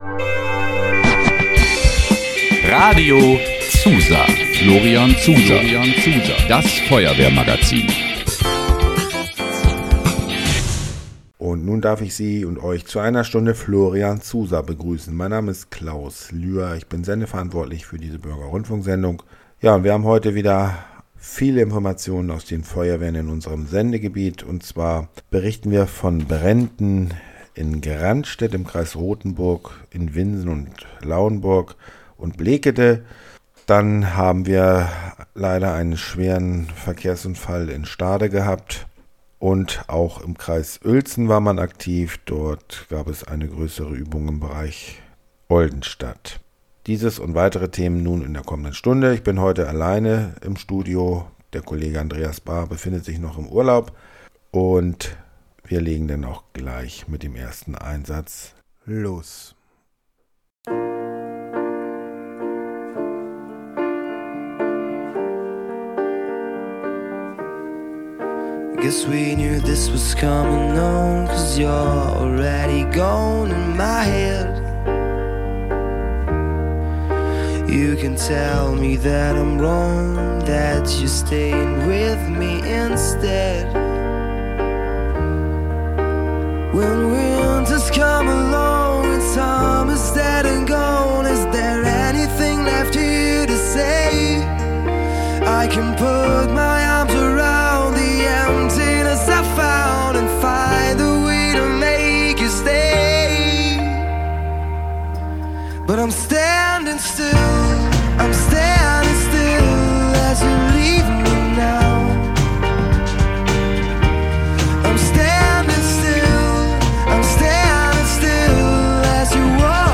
Sendungsmitschnitt Florian ZuSa-Sendung am 06.10.24 (Wdh. am 13.10.24)